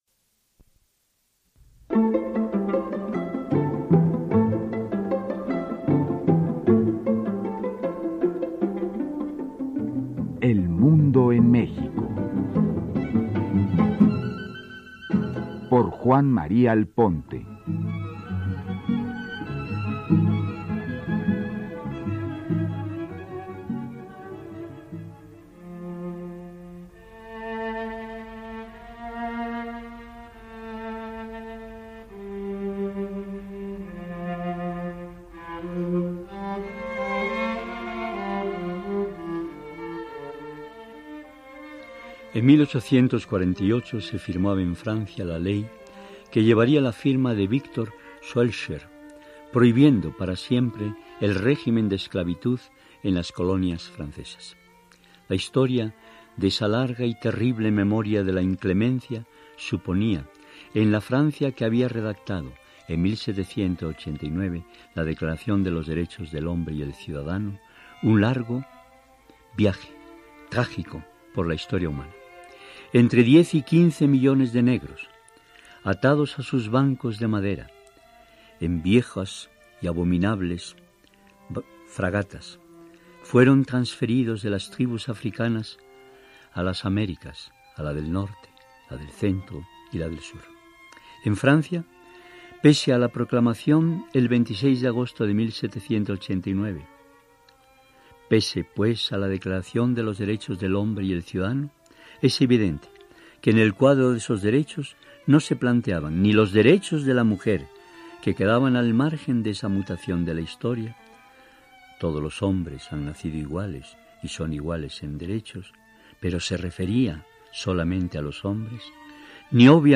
Desde el 2 de diciembre de 1949 se trata de la erradicación de las formas contemporáneas de la esclavitud, como la trata de personas, la explotación sexual, las peores formas de trabajo infantil, el matrimonio forzado y el reclutamiento forzoso de niños para utilizarlos en conflictos armados. Escucha a Juan María Alponte, hablar sobre el tema de la esclavitud, en su programa “El Mundo en México”, transmitido en 2001.